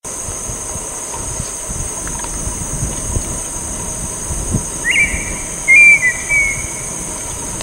Colorada (Rhynchotus rufescens)
Nombre en inglés: Red-winged Tinamou
Fase de la vida: Adulto
País: Argentina
Condición: Silvestre
Certeza: Vocalización Grabada